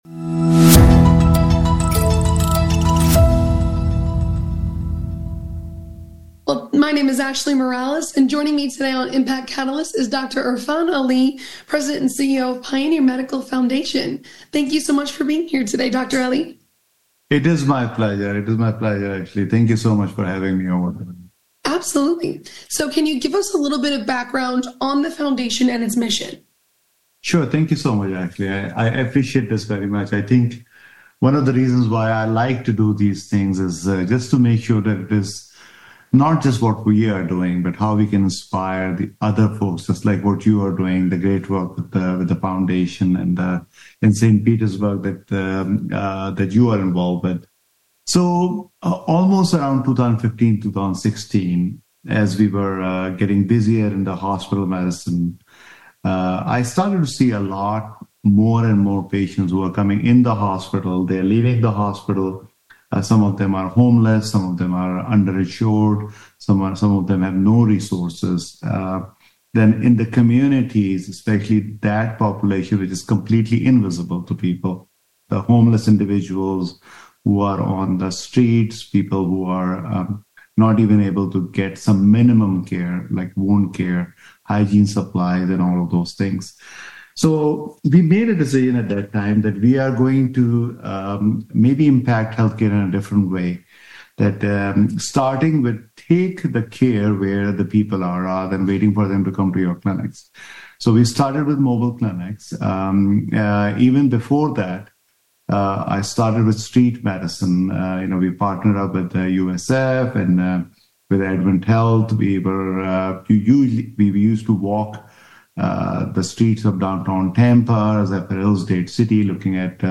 Impact Catalyst Interview